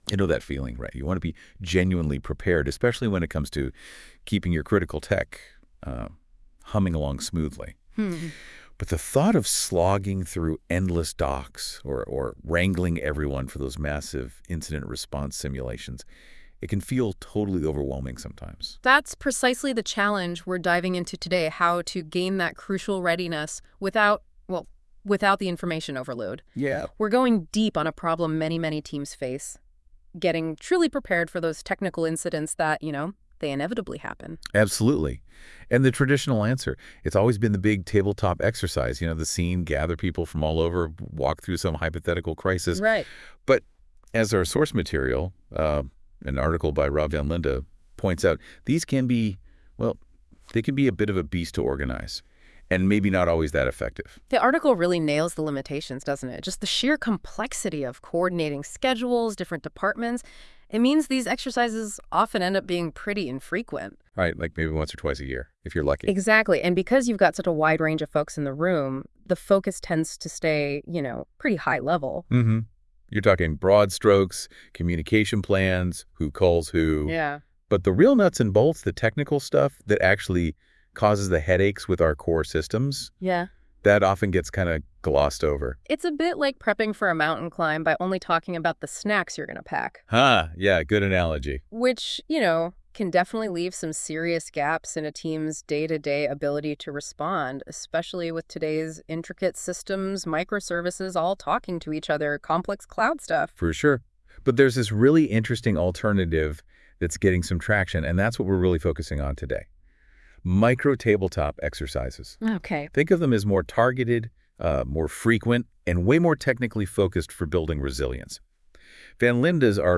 Podcast generated by NtebookLM, based on the current Post.